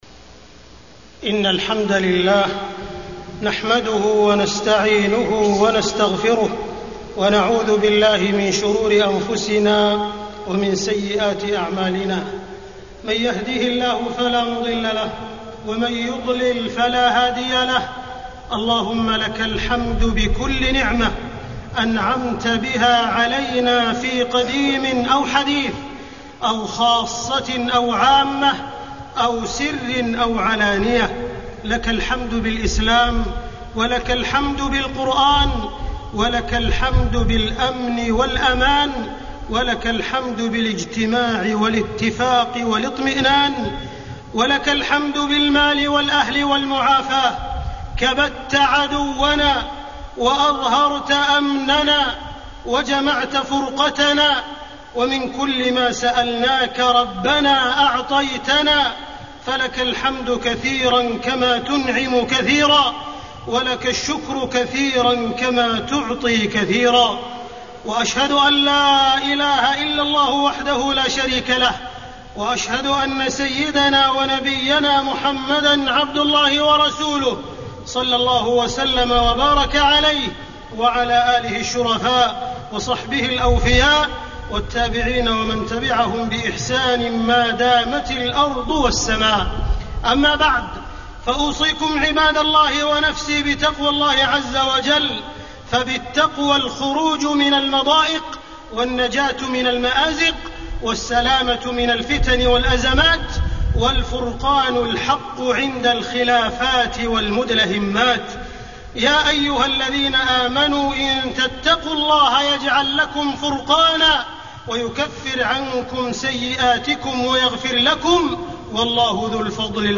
تاريخ النشر ١٧ ربيع الثاني ١٤٢٨ هـ المكان: المسجد الحرام الشيخ: معالي الشيخ أ.د. عبدالرحمن بن عبدالعزيز السديس معالي الشيخ أ.د. عبدالرحمن بن عبدالعزيز السديس إنجازان تاريخيان